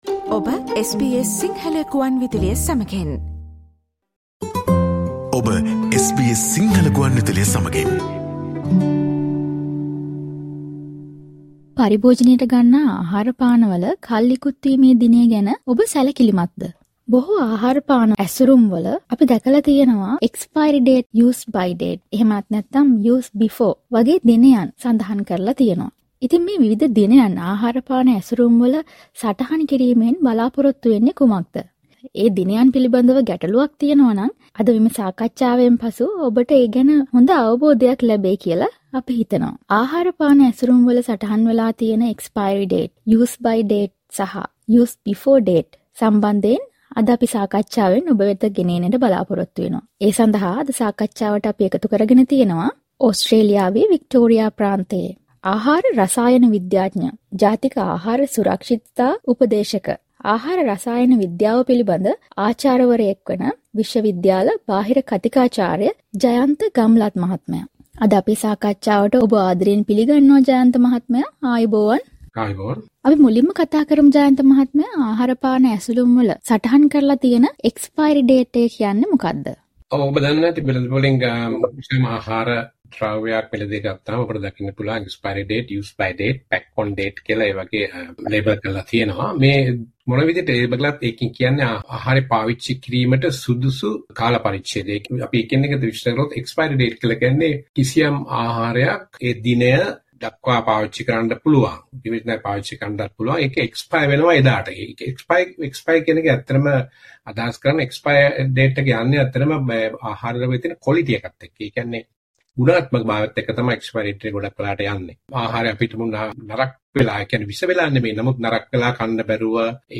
ආහාර පාන ඇසුරුම් වල එම ආහාර පාන කල් ඉකුත් වීම සම්බන්ධයෙන් සටහන් වී තිබෙන දින පිලිබදව වඩා හොද අවබෝධයක් මෙම සාකච්චාවට සවන් දීමෙන් ඔබට ලබාගන්න පුළුවන්.